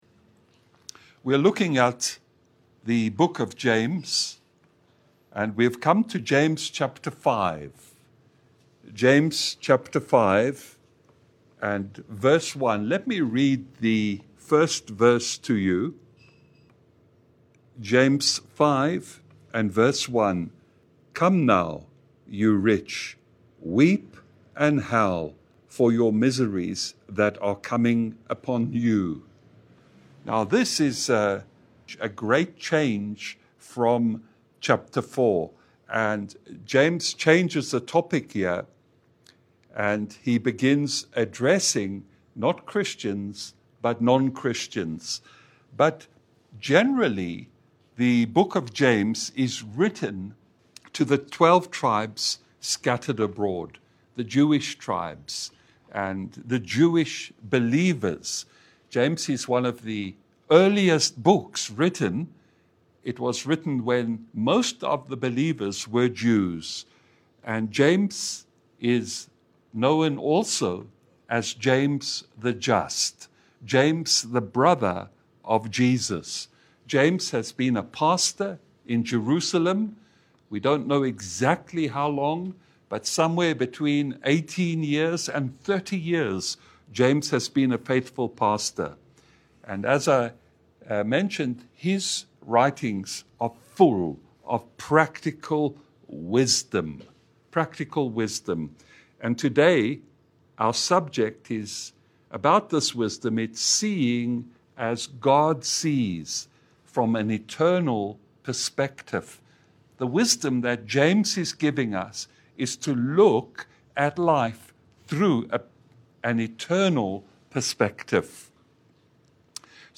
James 5:1 Service Type: Lunch hour Bible Study « Invited but not interested.